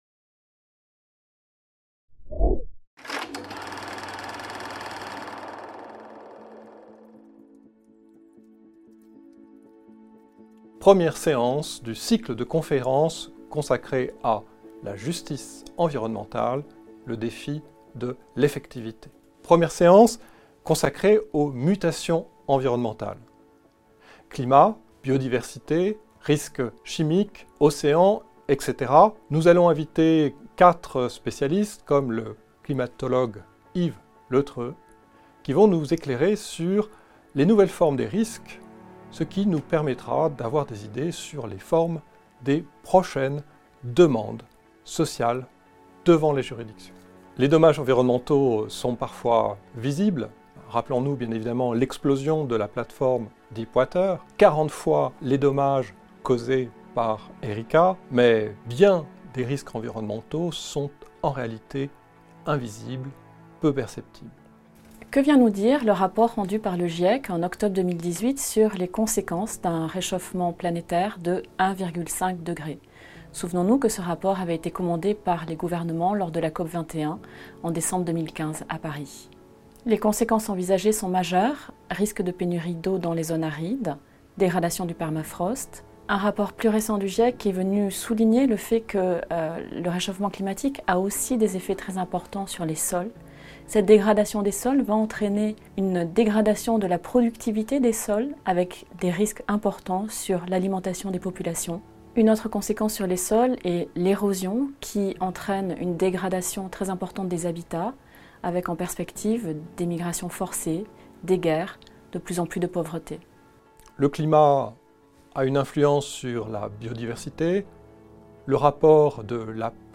Conférence inaugurale : Mutations environnementales
Programme 2020/2021 du cycle bi-annuel de conférences à la Cour de cassation